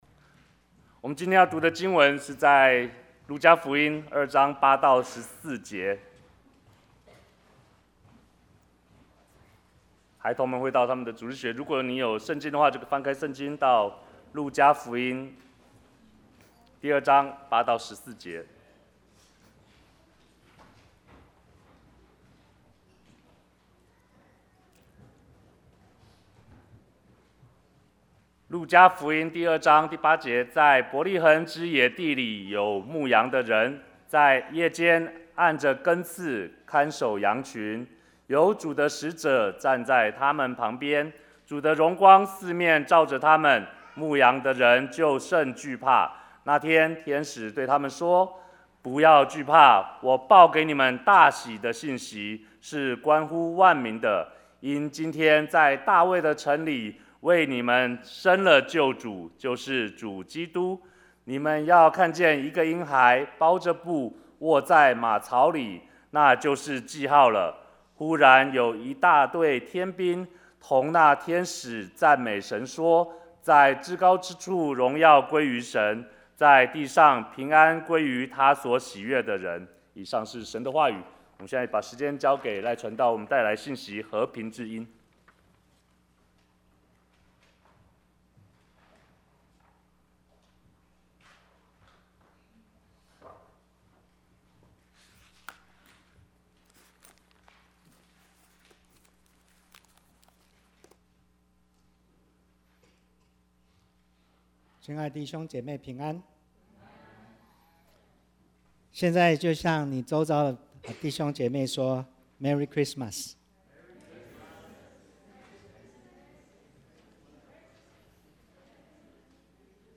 Bible Text: 路加福音 2:8-14 | Preacher